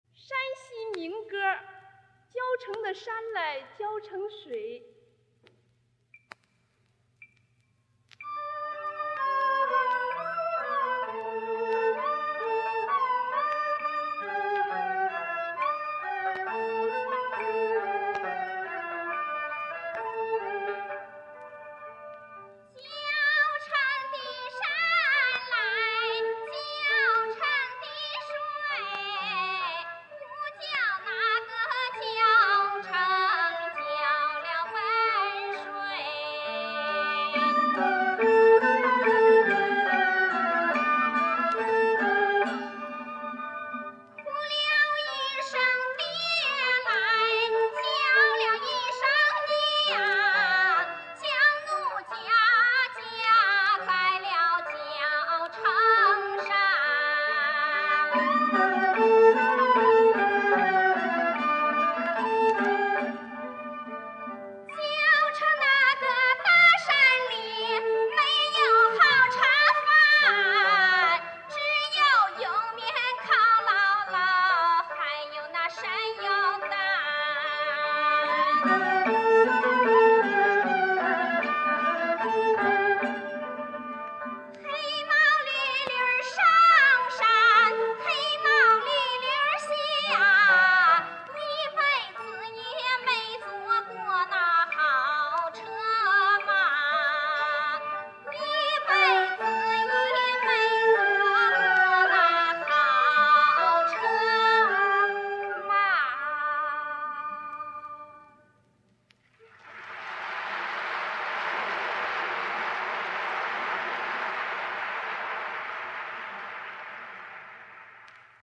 [15/1/2023]歌唱家郭兰英在国庆十周年文艺晚会上演唱山西民歌《交城的山来交城的水》（四段版 1959年） 《交城的山来交城的水》（山西民歌） 演 唱：郭兰英 伴 奏：民族乐队 1959年10月 交城的山来交城的水 不浇那个交城浇了文水 哭了一声爹来叫了一声娘 将奴家嫁在了交城山 交城那个大山里没有好茶饭 只有莜面栲栳栳还有那山药蛋 黑毛驴驴上山黑毛驴驴下 一辈子也没坐过那好车马 一辈子也没坐过那好车马